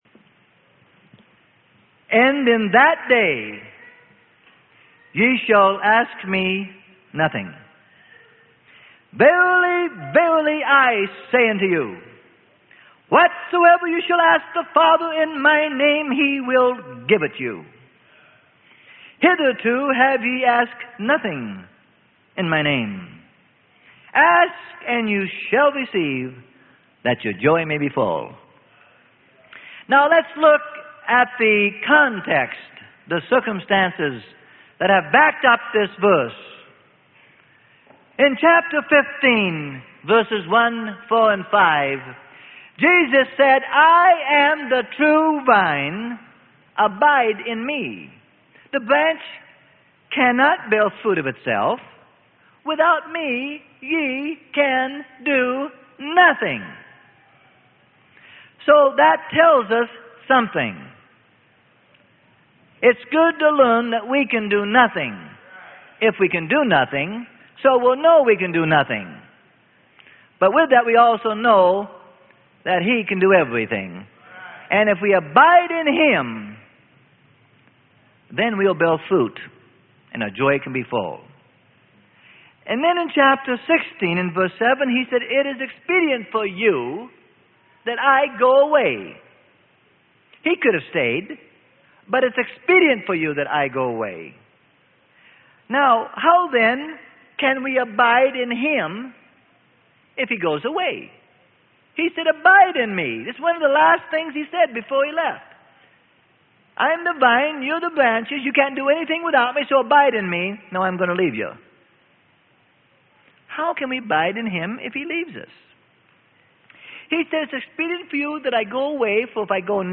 Sermon: Asking In Jesus' Name - Freely Given Online Library